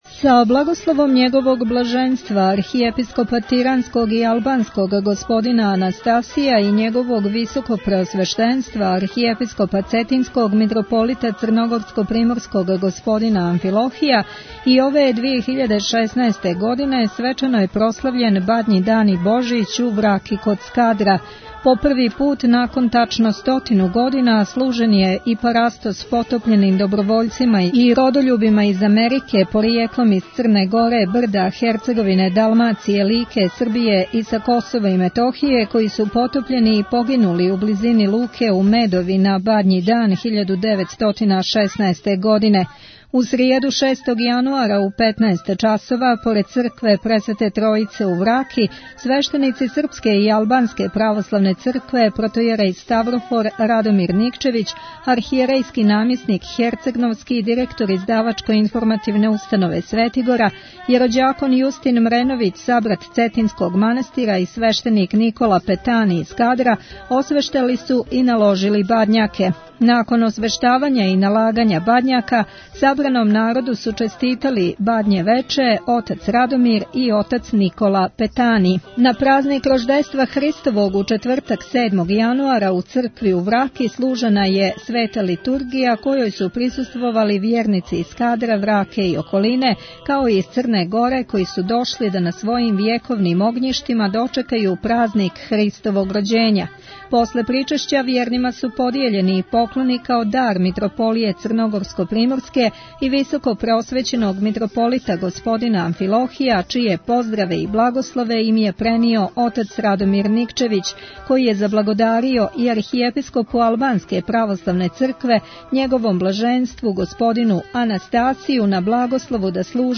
Прослава Бадњег дана и Божића у Скадру и парастос родољубима у Медови
Извјештаји